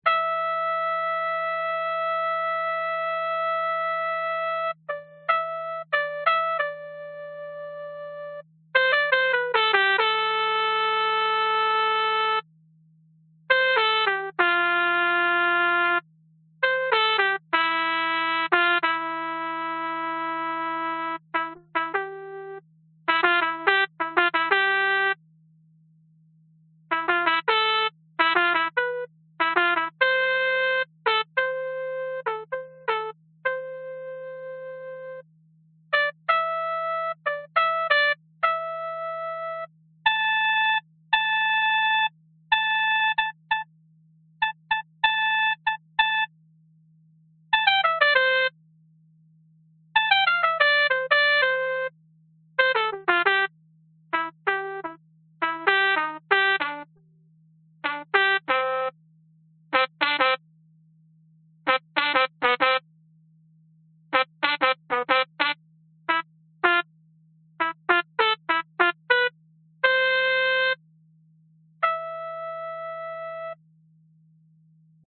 标签： 器乐 冷酷